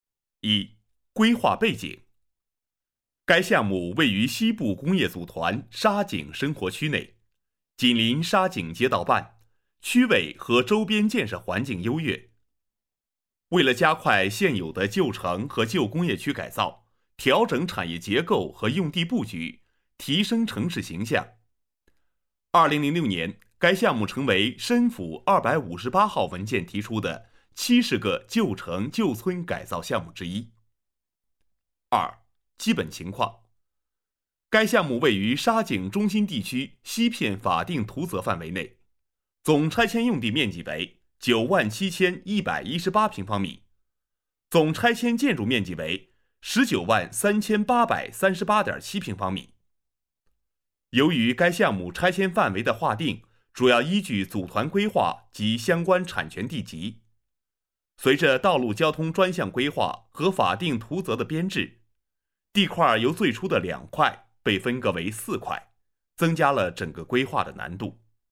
多媒体配音是指对应于各种媒介的人声解说配音，多媒体配音包括工程规划配音、城市规划配音、学校规划解说、建筑方案解说、系统设计方案解说、产品功能解说等。
男声配音
多媒体男国78B